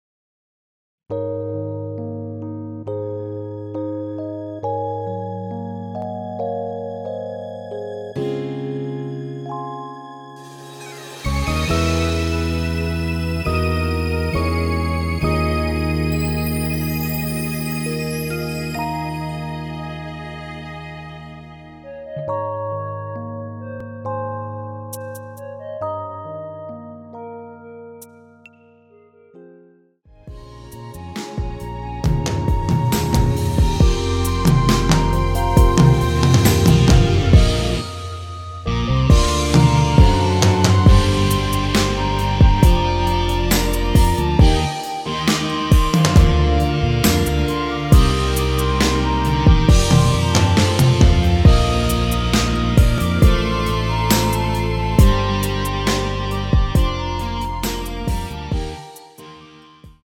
원키 멜로디 포함된 MR입니다.(미리듣기 확인)
Ab
앞부분30초, 뒷부분30초씩 편집해서 올려 드리고 있습니다.
중간에 음이 끈어지고 다시 나오는 이유는